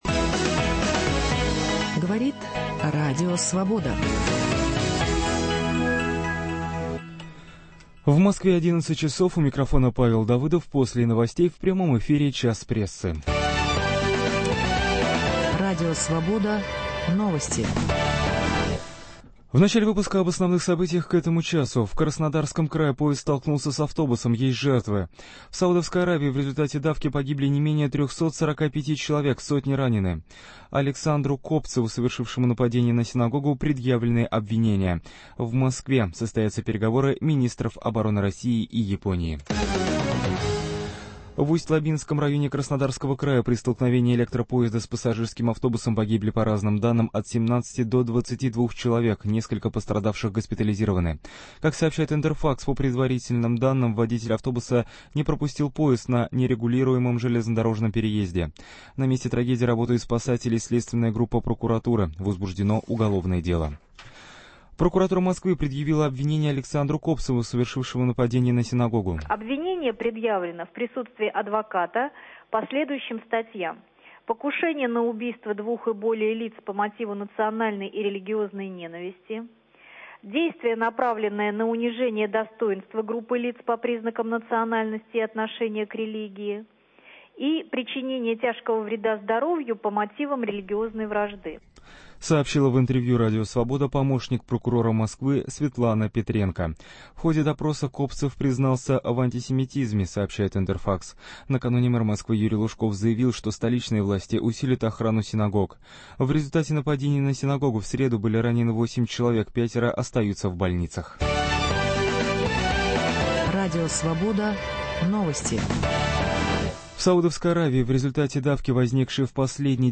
Утром в газете, а с 11 до полудня - обсуждение в прямом эфире самых заметных публикации российской и зарубежной печати. Их авторы и герои - вместе со слушателями.